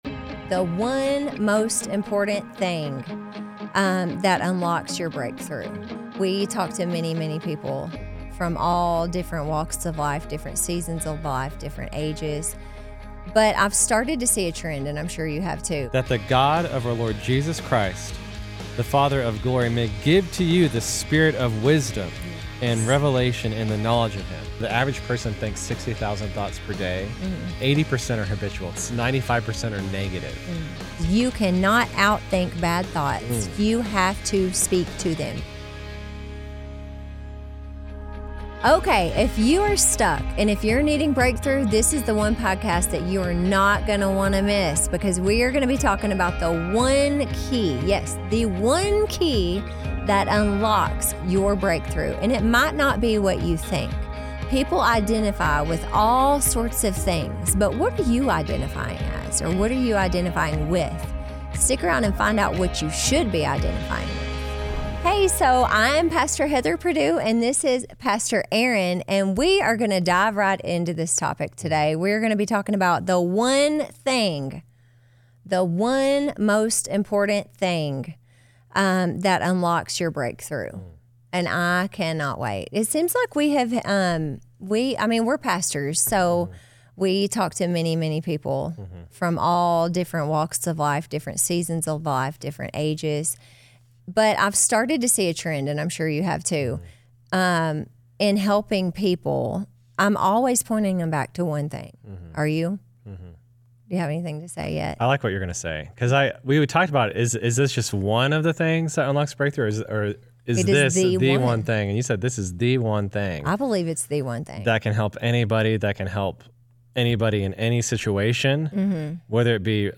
Whether you’re a young professional grinding through uncertainty or a new parent feeling overwhelmed, this conversation will fire you up to live boldly and joyfully.